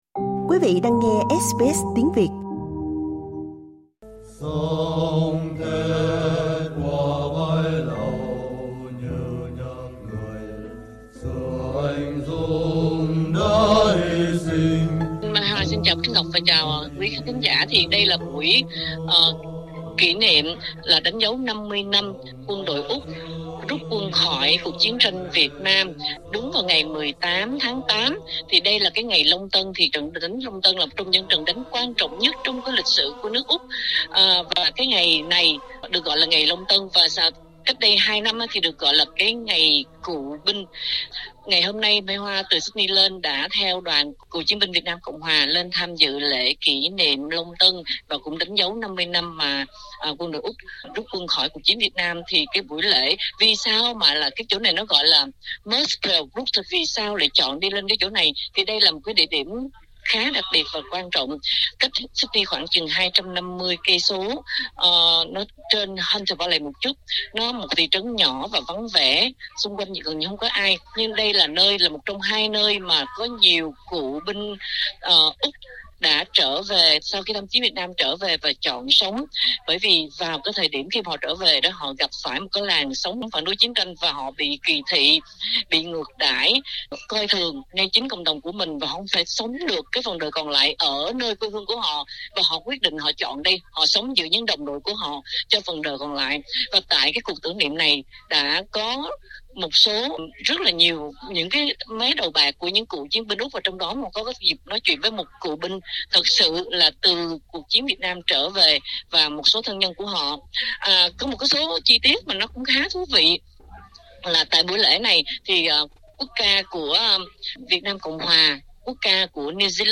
Mời quý vị nhấn vào audio để nghe tường thuật buổi lễ.